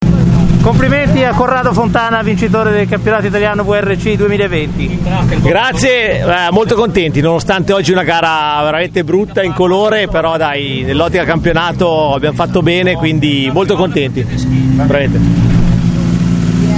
Interviste al Rally Due Valli
Interviste di fine rally